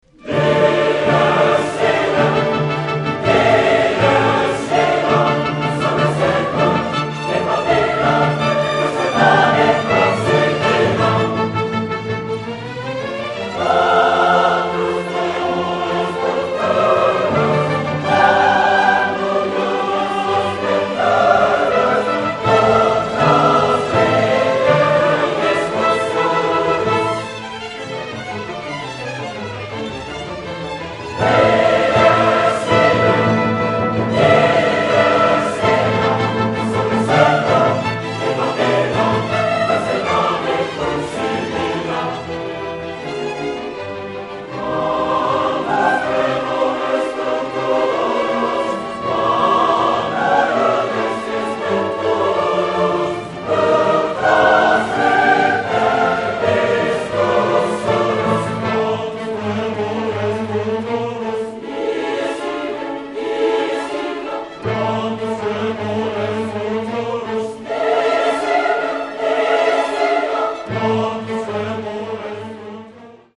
横浜オラトリオ協会合唱団 - 試聴室
こちらでは、最近の演奏会の録音から、その一部分を１分ほどお聞きいただけます。
第７４回定期演奏会(2010年) モーツァルト作曲「レクイエム」より